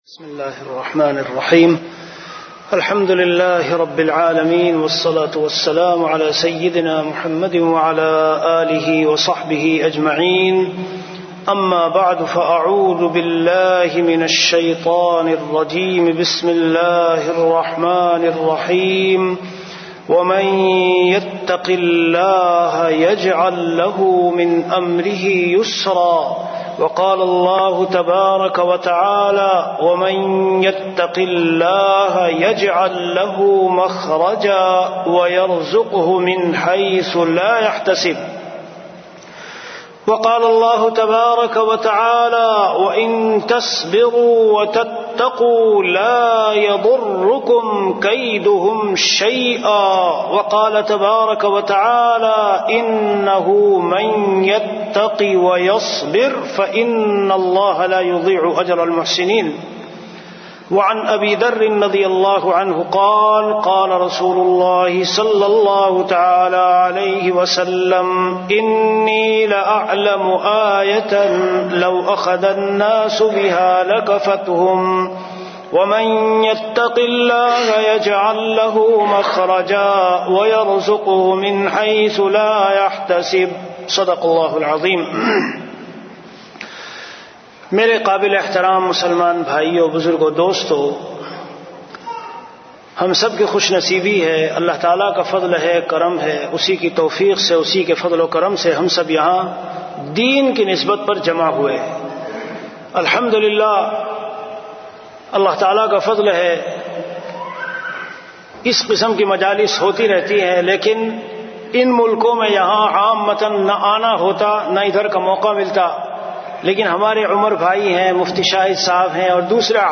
An Islamic audio bayan
Delivered at Qatar.